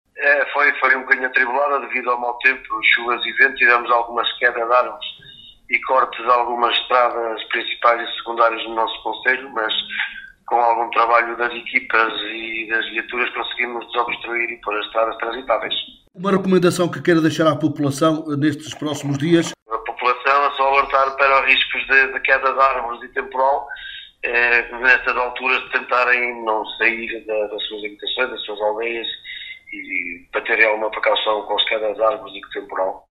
em declarações à Alive FM